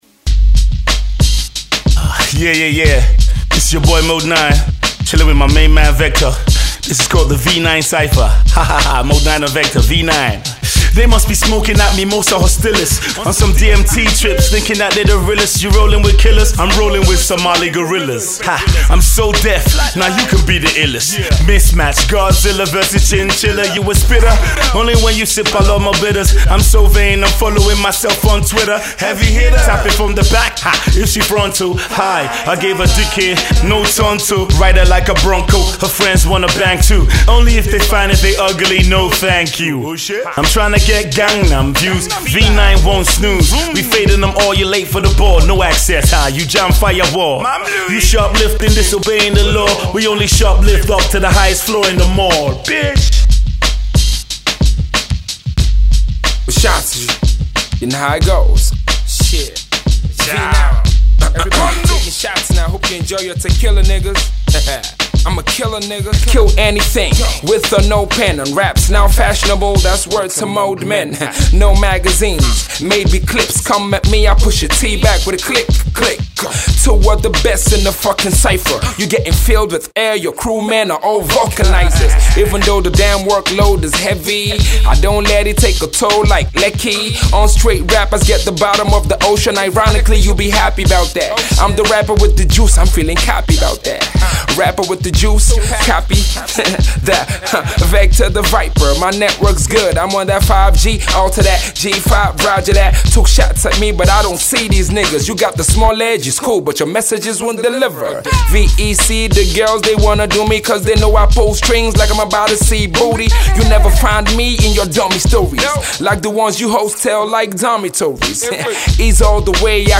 rappers